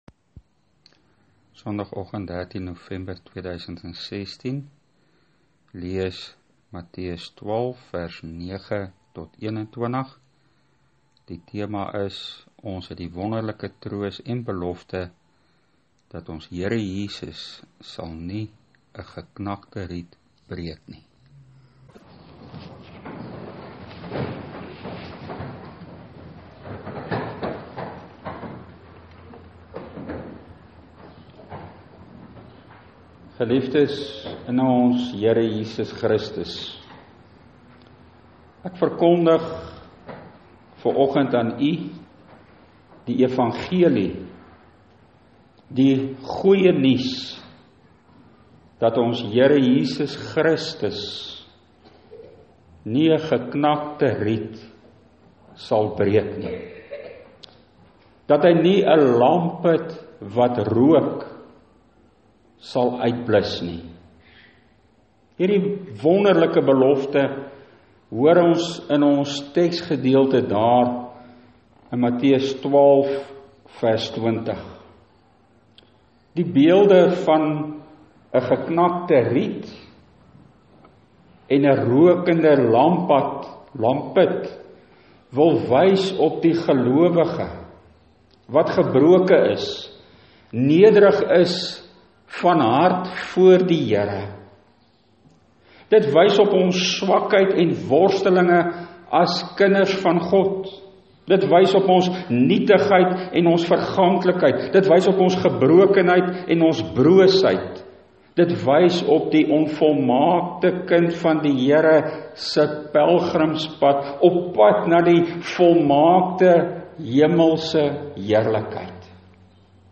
Preek: Matteus 12:20 Ons Here Jesus sal nie ‘n geknakte riet verbreek nie